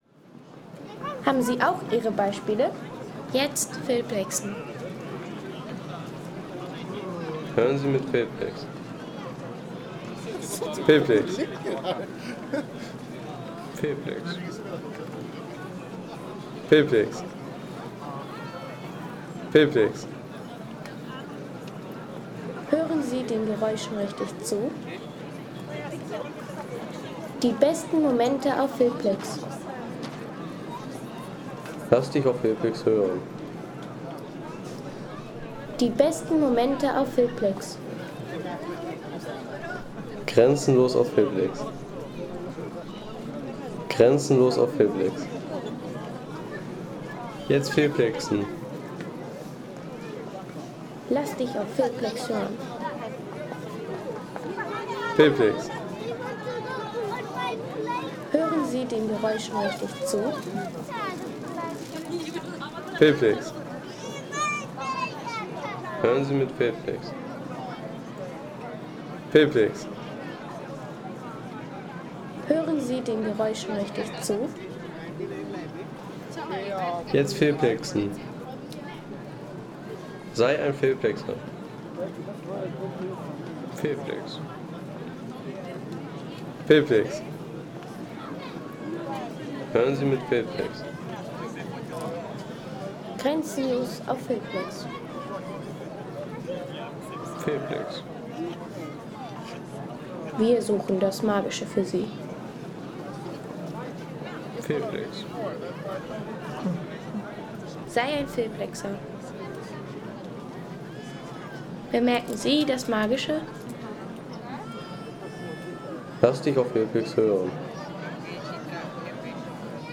Weihnachtsmarkt in Prag
Magische Klänge des Weihnachtsmarktes am Altstädter Ring in Prag.